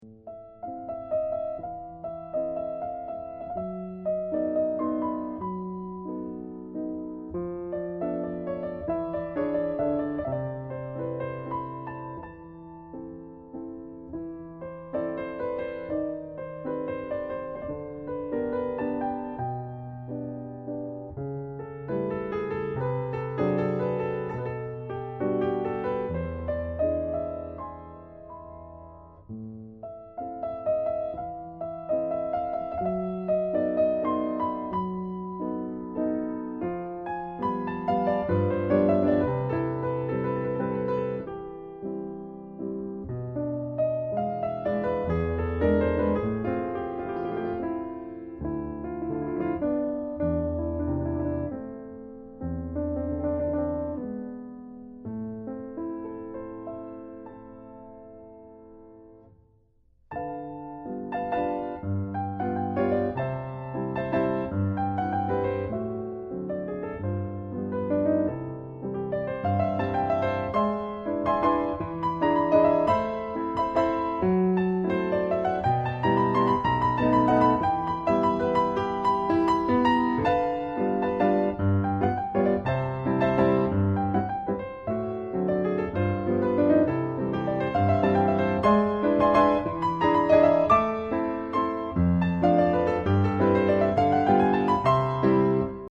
巴西作曲家Nazareth / 鋼琴作品